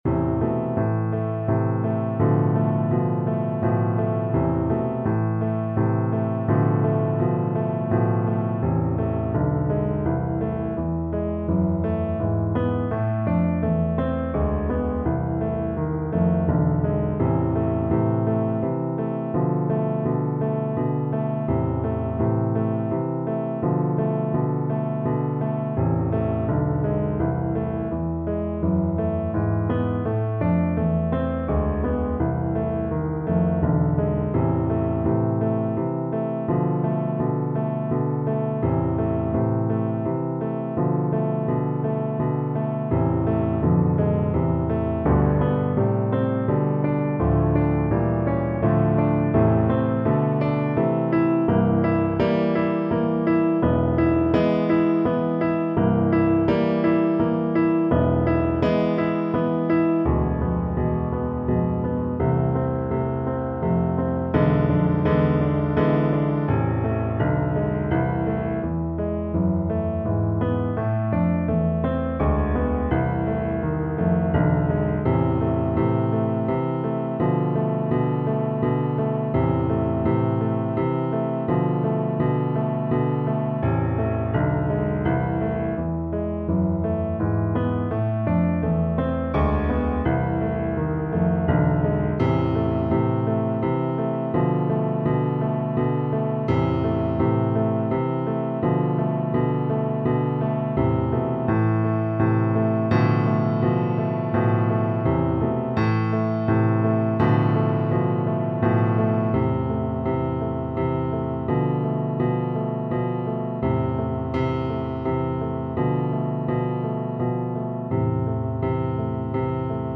Moderato =c.84
3/4 (View more 3/4 Music)
Classical (View more Classical Bassoon Music)